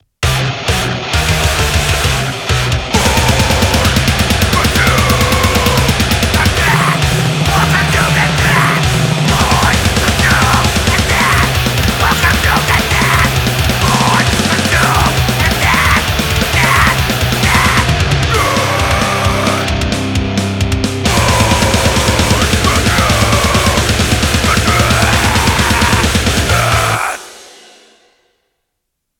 Grindcore / Thrashgrind
Just pure throat-shredding panic.